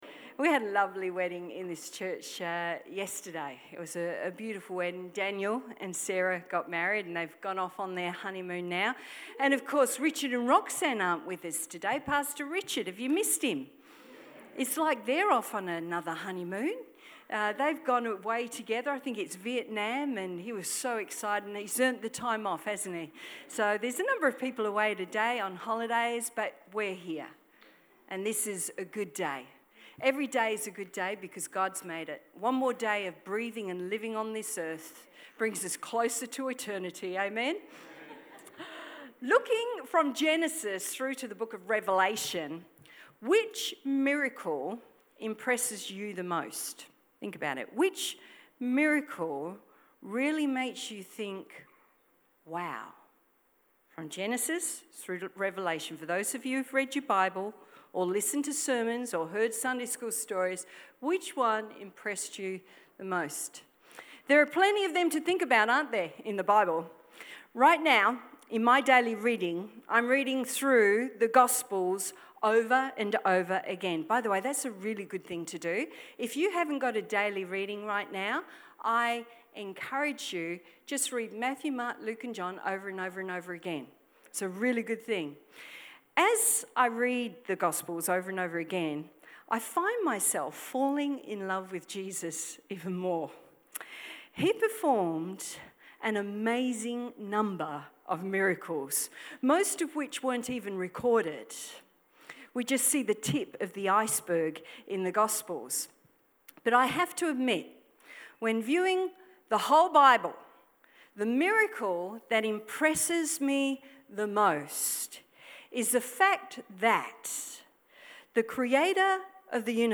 Main points of the sermon Voice your concerns to Jesus.